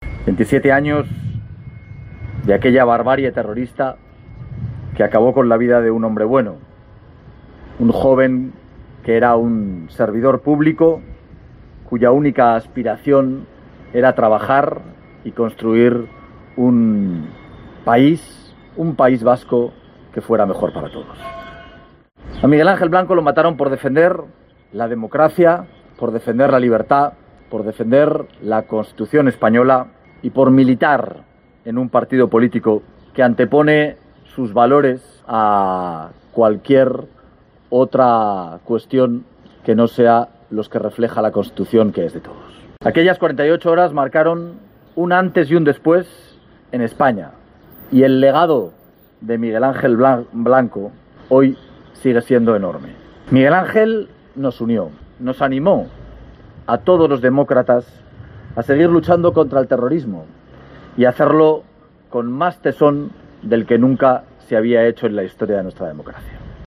Coincidiendo con el 27 aniversario de su secuestro y posterior asesinato a manos de ETA, la plaza San Francisco ha acogido este miércoles un homenaje al concejal del PP en Érmua
El presidente del PP en Aragón, Jorge Azcón, ha recordado la figura de Miguel Ángel Blanco